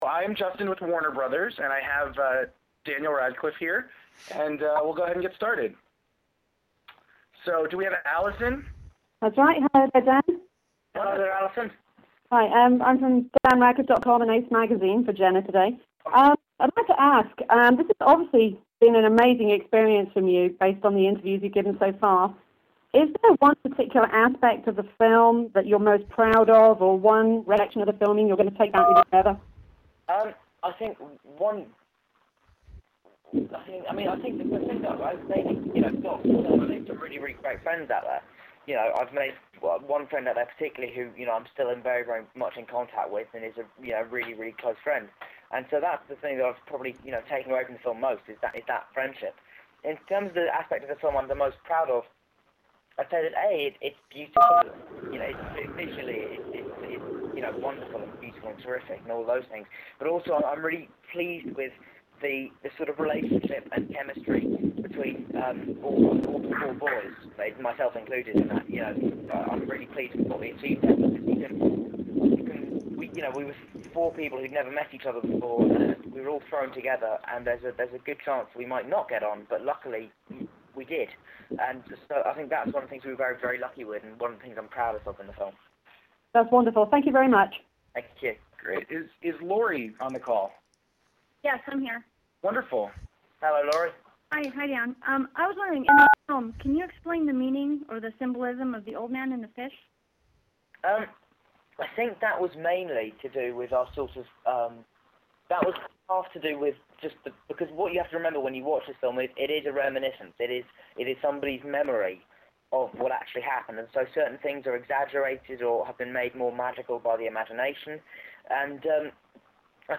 HPANA and other media were invited today to participate in a conference call with actor Daniel Radcliffe about his new movie, December Boys.